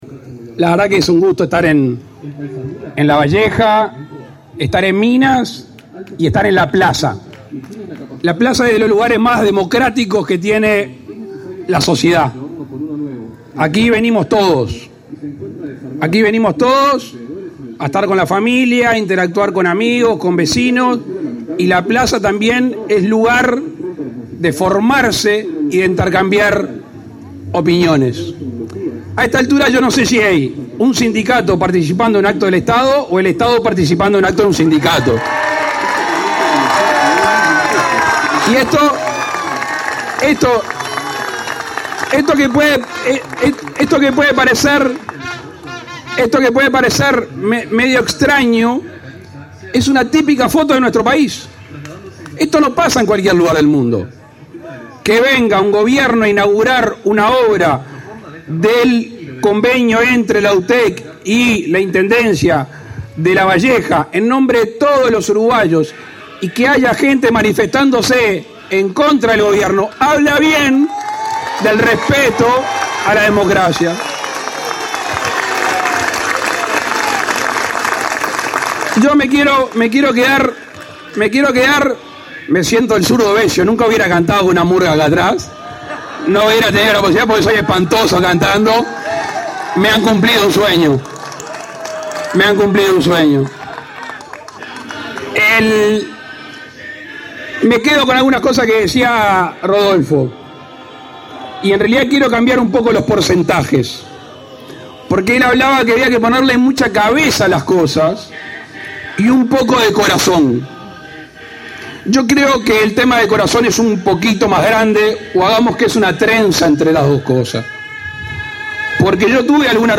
Palabras del presidente Luis Lacalle Pou
El presidente Luis Lacalle Pou encabezó este lunes 14 la inauguración de una sede de la Universidad Tecnológica (UTEC) en Minas, departamento de